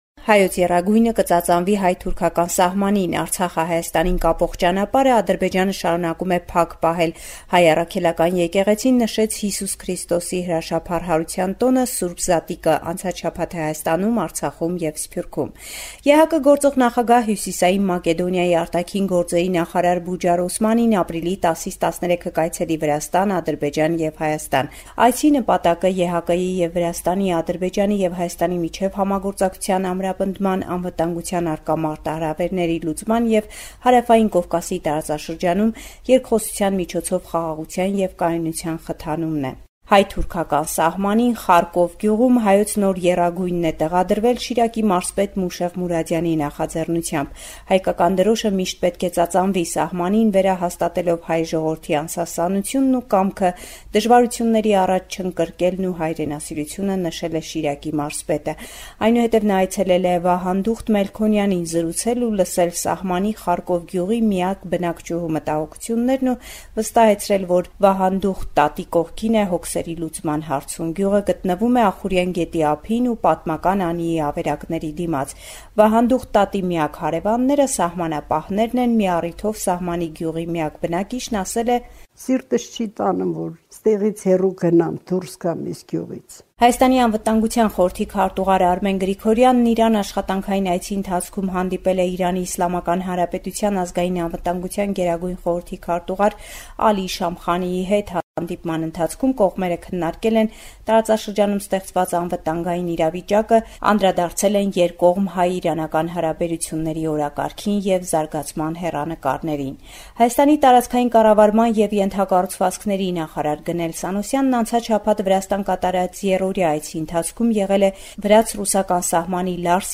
Latest News from Armenia – 11 April 2023